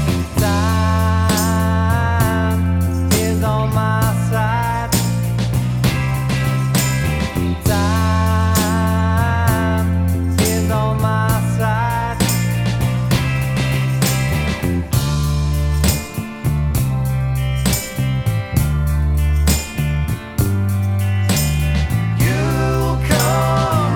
Minus Guitars Rock 3:06 Buy £1.50